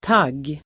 Uttal: [tag:]